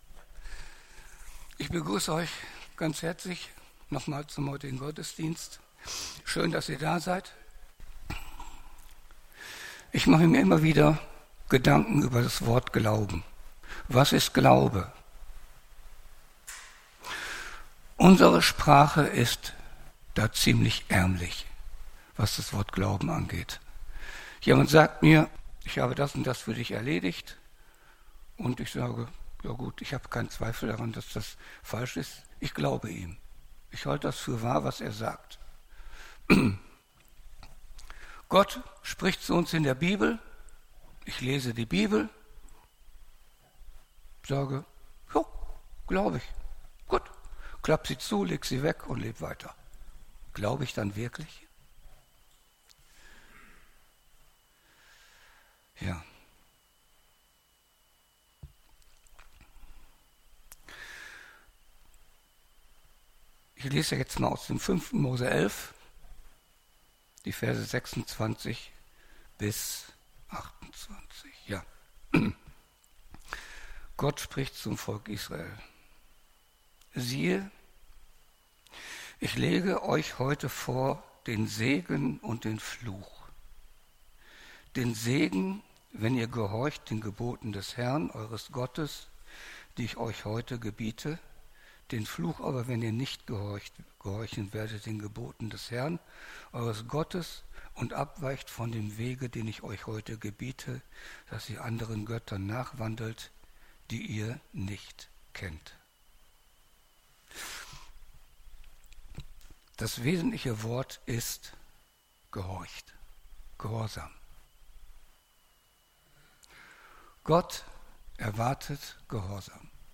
Aktuelle Predigten der Markusgemeinde Emsdetten; Wir feiern jeden Sonntag in der Martin-Luther-Kirche in Emsdetten (Neubrückenstrasse 96) unseren Gottesdienst.